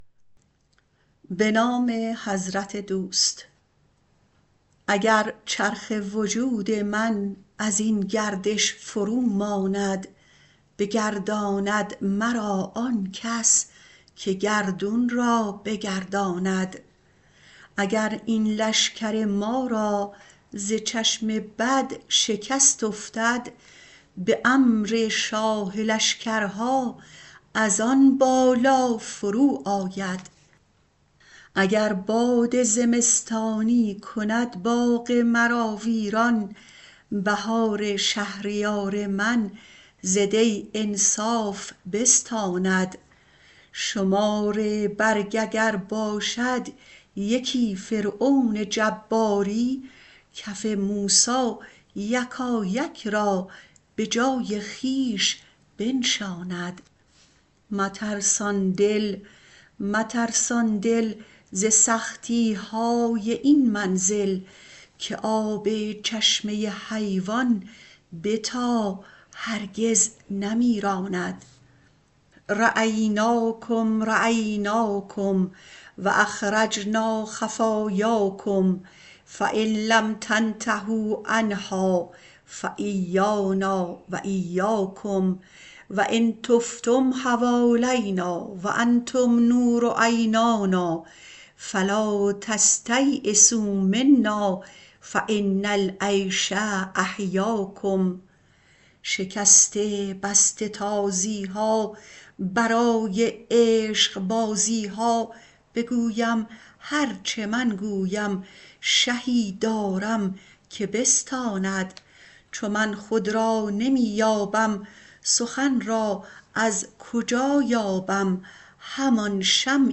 مولانا دیوان شمس » غزلیات غزل شمارهٔ ۵۹۲ به خوانش